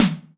9SNARE.wav